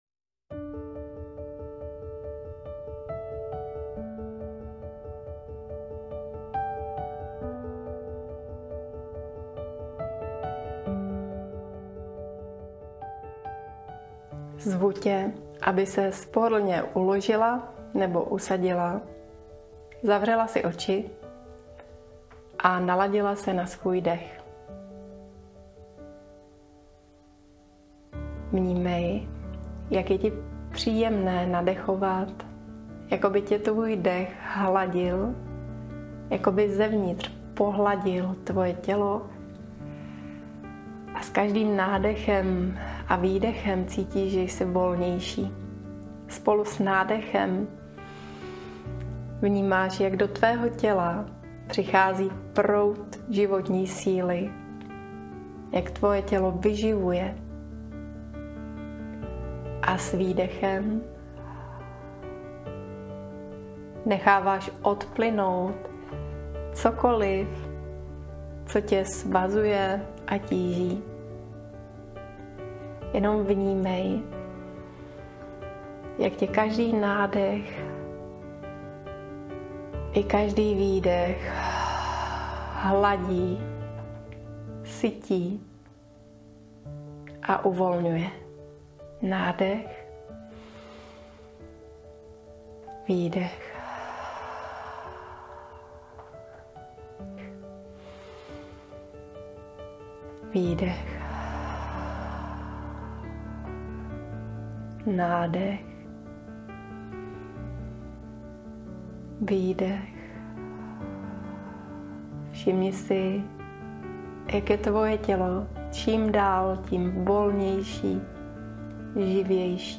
MEDITACE
MEDITACE-Otevri-se-lasce-a-vysli-ji-do-sveta.mp3